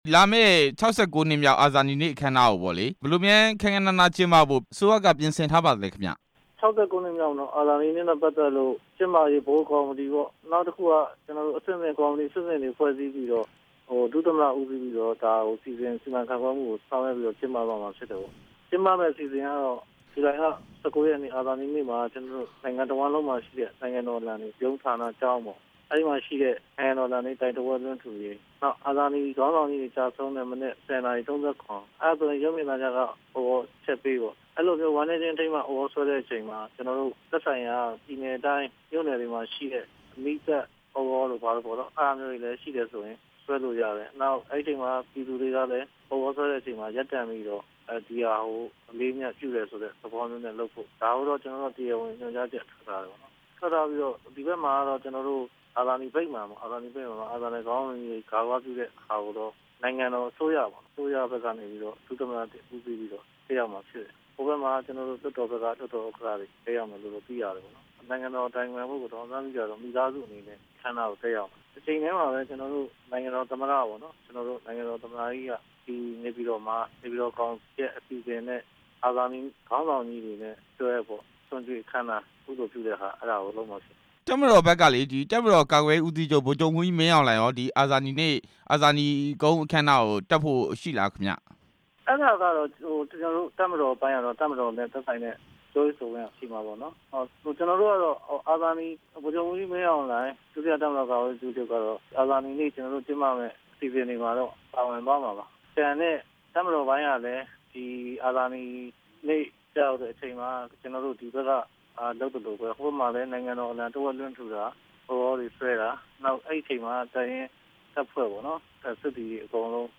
ဦးဇော်ဌေး ကို ဆက်သွယ်မေးမြန်းချက်